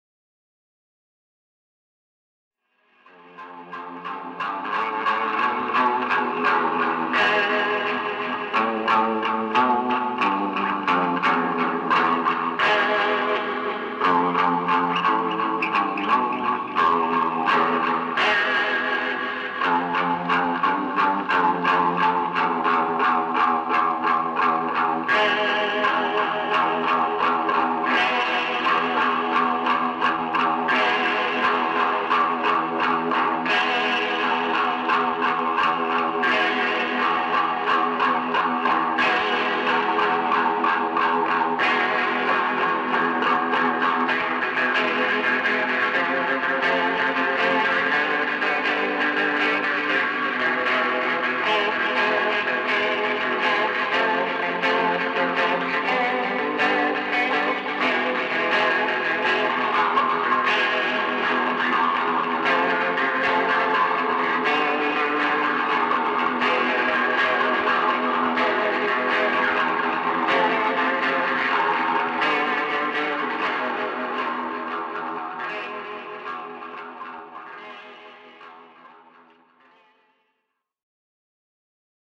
keening, crooning, howling vocals